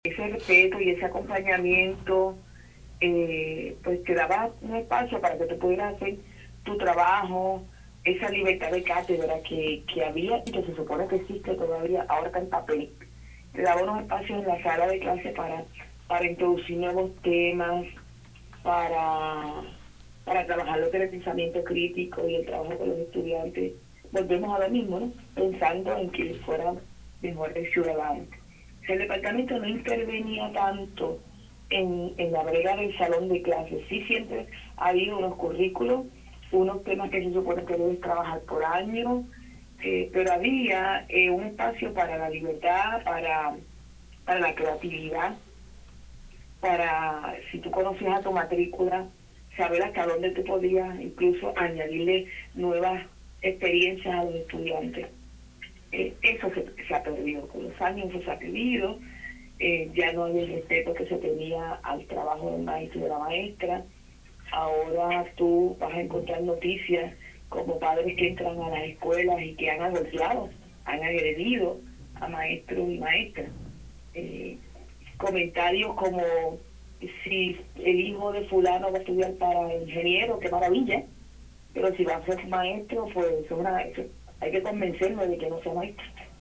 en entrevista con Radio Isla